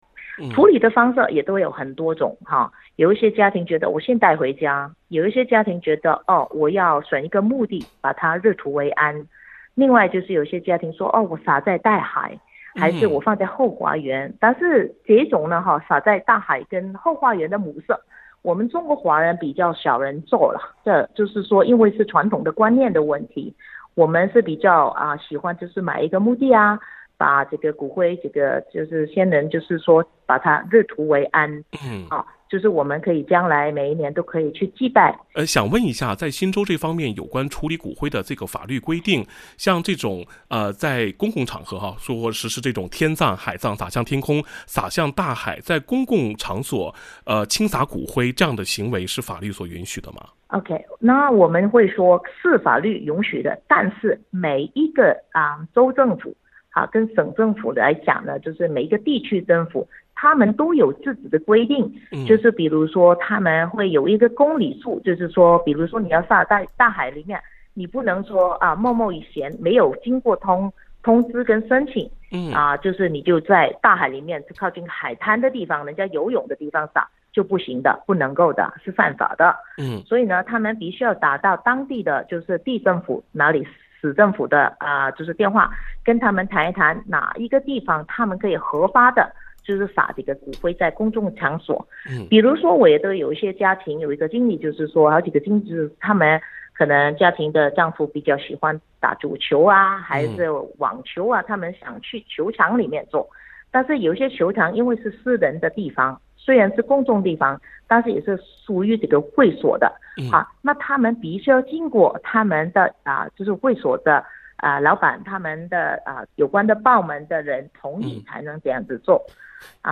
在将骨灰做有机处理后，永生树把一位或多位逝者的骨灰作为开枝散叶的养料，不仅让逝者骨血通过这种特别的方式重获新生，还让生前的亲情纽带在死后得以延续。(点击封面图片，收听采访录音)